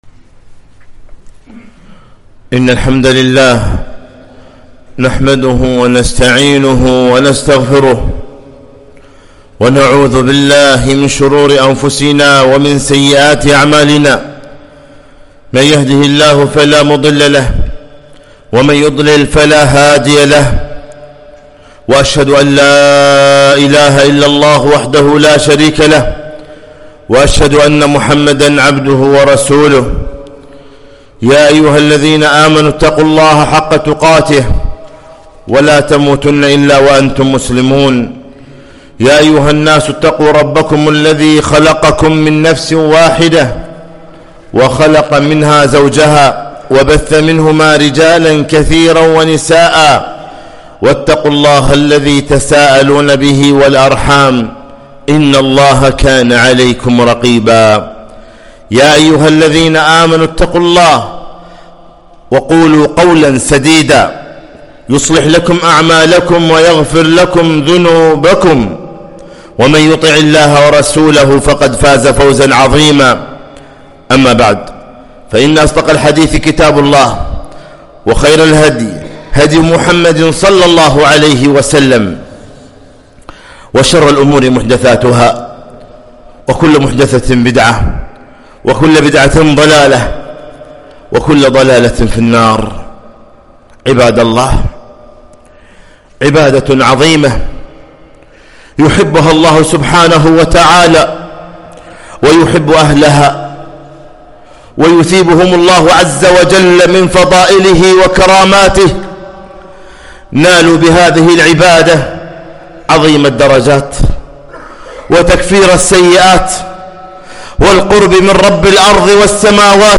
خطبة - ( ألا بذكر الله تطمئن القلوب)